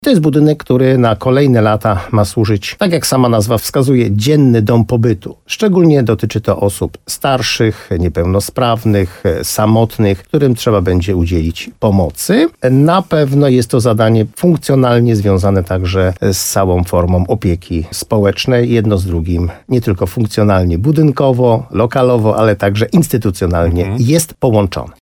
– Stanie on w bezpośrednim sąsiedztwie Gminnego Ośrodka Pomocy Społecznej, z którym będzie funkcjonalnie połączony windą i przewiązką – mówił w programie Słowo za Słowo w radiu RDN Nowy Sącz wójt gminy Moszczenica Jerzy Wałęga.
Rozmowa z Jerzym Wałęga: Tagi: Słowo za Słowo Gorlice Jerzy Wałęga gmina Moszczenica Dzienny Dom Pobytu